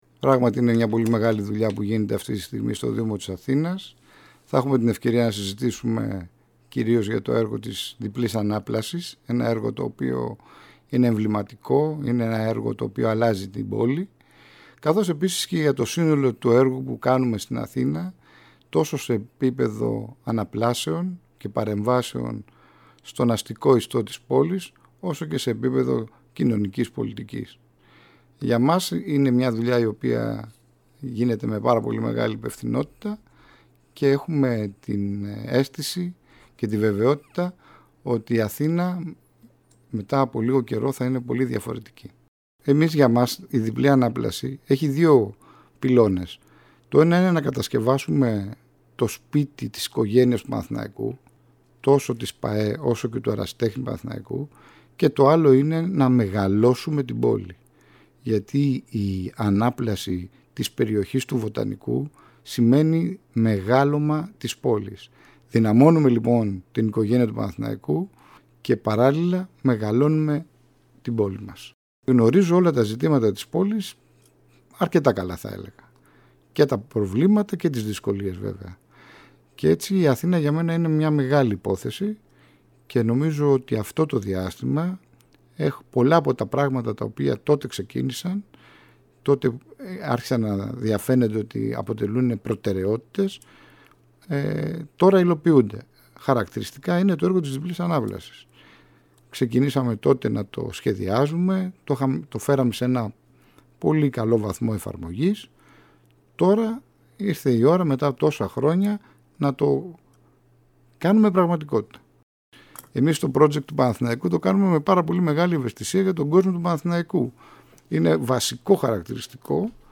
Στην εκπομπή “Πρόσωπα” της ΕΡΑΣΠΟΡ, μίλησε ο Γενικός Γραμματέας του Δήμου Αθηναίων και υπεύθυνος-συντονιστής του έργου της Διπλής Ανάπλασης.